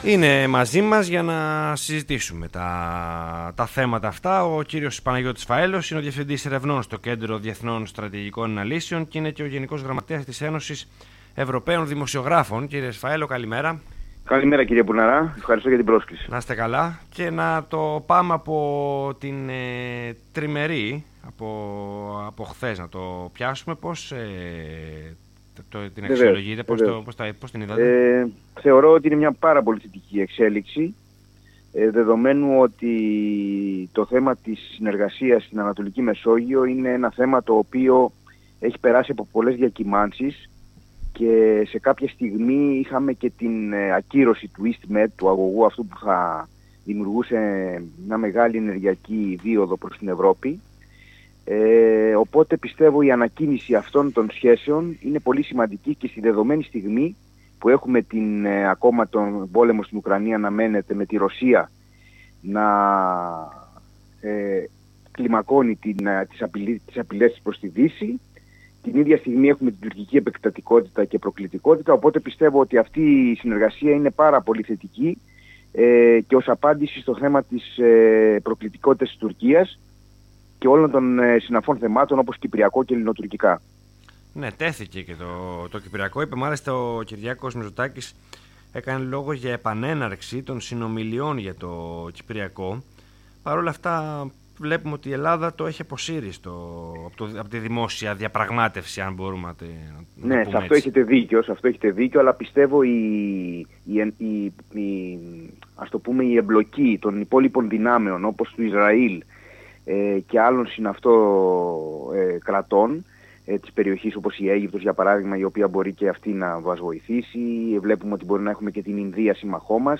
ΣΥΝΕΝΤΕΥΞΗ